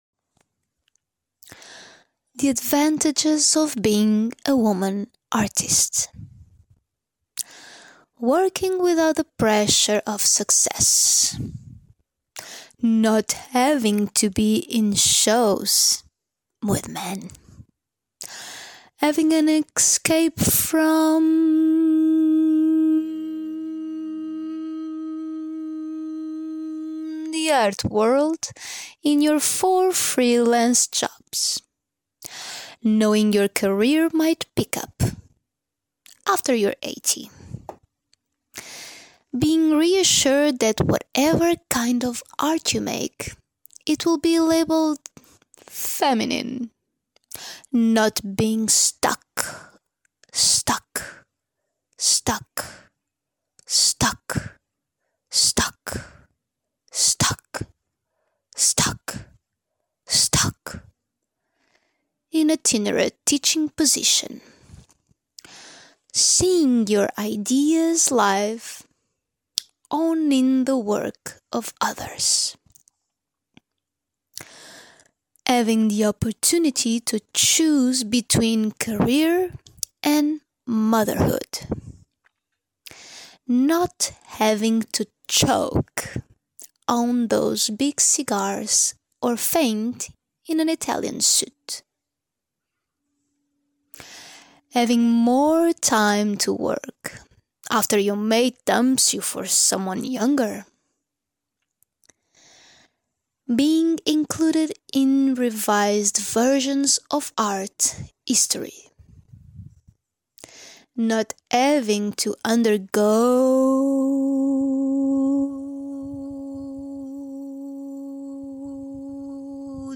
Title Manifesto_Guerrilla girls Description Reading of Guerrilla girls manifesto. Can be used has a score to improvise or to compose.